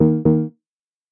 Звуки запрета
В коллекции представлены различные варианты предупреждающих сигналов, блокировок и системных оповещений.